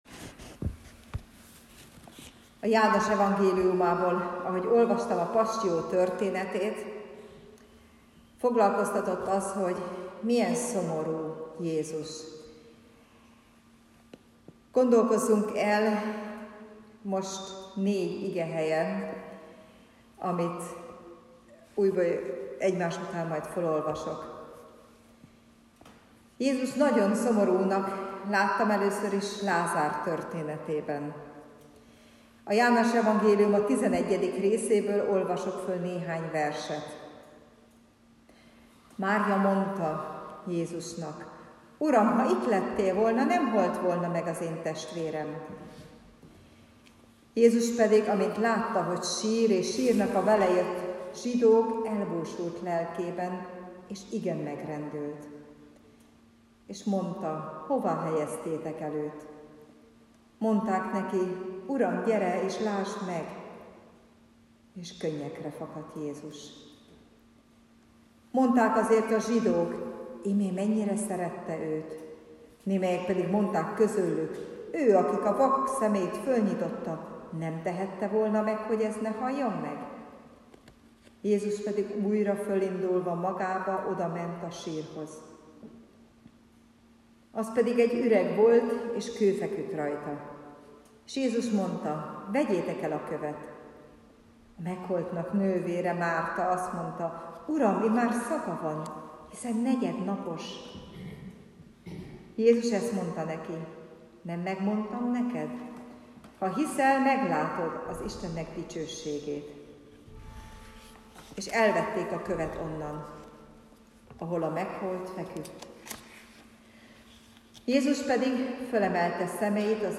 2018-as és korábbi Istentiszteleteink hanganyaga: